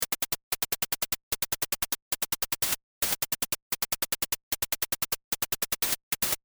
チップチューン ハイハットクローズ
チップチューン ハイハットオープン
ハイハット音色サンプル（MP3）
波形はNoise（ホワイトノイズ）を使い、ADSRでごく短くしたホワイトノイズを、ハイハットの音色として利用します。
SusLevelをゼロ、Decayを短くすれば『チッ』というクローズハイハットの音。
SusLevelを上げたり、Decayを長くすれば『チー』というオープンハイハットのような音。